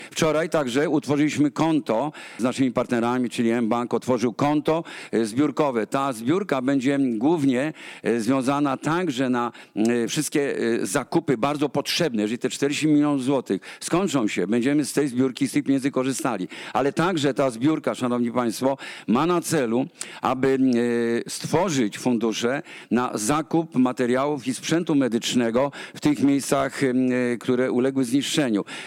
Poza akcją dostarczania produktów pierwszej potrzeby, WOŚP utworzył też zbiórkę pieniędzy. O szczegółach mówi Jerzy Owsiak.